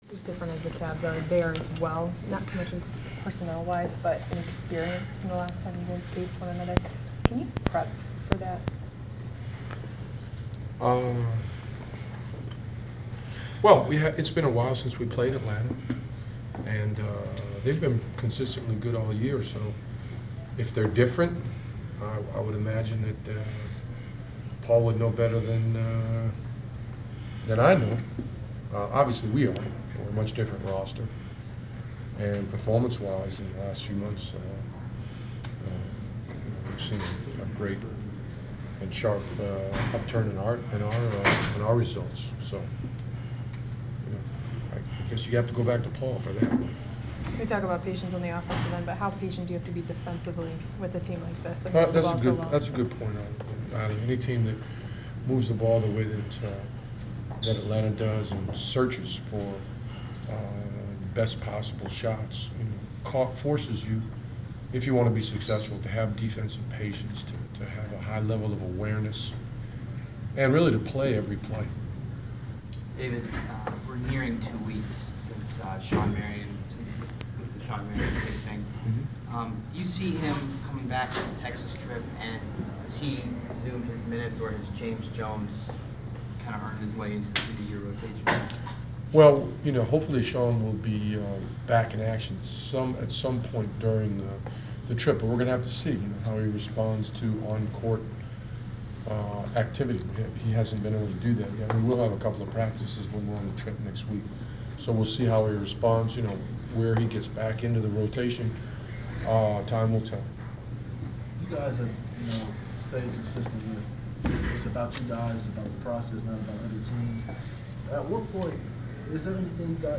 Inside the Inquirer: Pregame presser with Cleveland Cavaliers’ head coach David Blatt